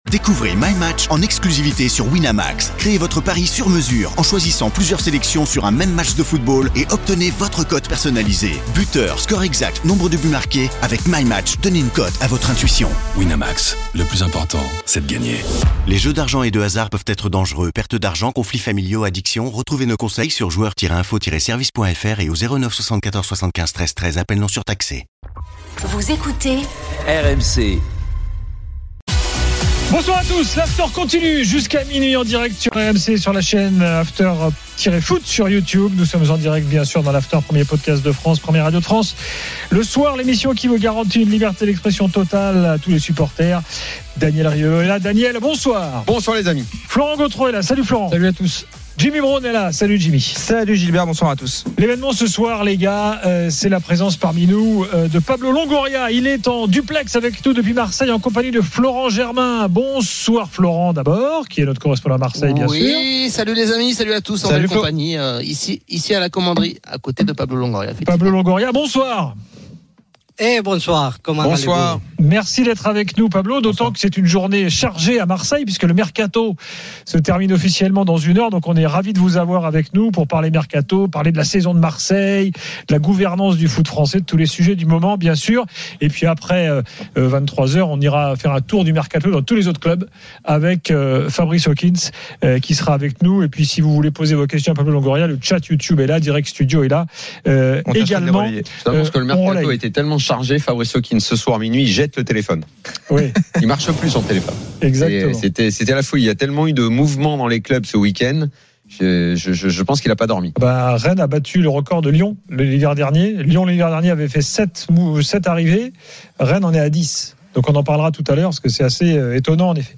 Le Top de l'After Foot : Invité de l'After, le Président de l'OM Pablo Longoria s'exprime sur le mercato d'hiver marseillais, Pogba et son Directeur sportif Medhi Benatia – 03/02
Chaque jour, écoutez le Best-of de l'Afterfoot, sur RMC la radio du Sport !
Les rencontres se prolongent tous les soirs avec Gilbert Brisbois, Daniel Riolo et Florent Gautreau avec les réactions des joueurs et entraîneurs, les conférences de presse d’après-match et les débats animés entre supporters, experts de l’After et auditeurs RMC.